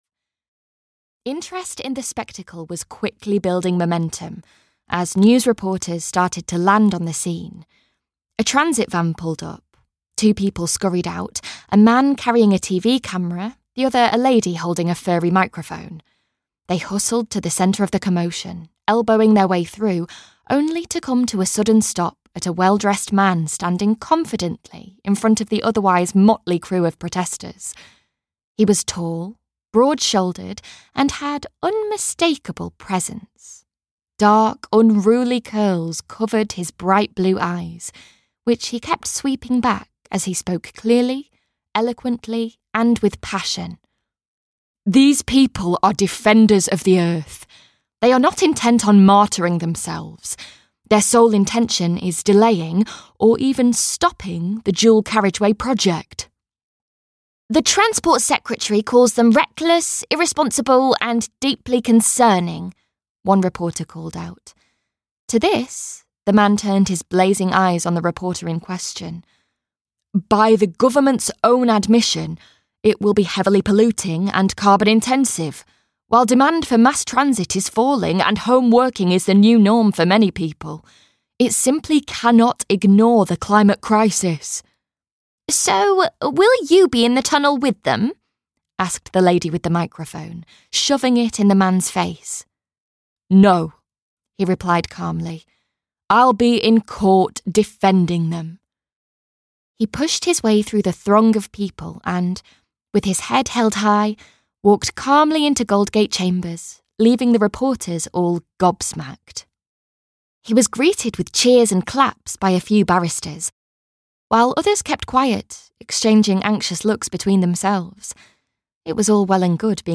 Return to Lilacwell (EN) audiokniha
Ukázka z knihy